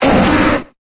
Cri de Cochignon dans Pokémon Épée et Bouclier.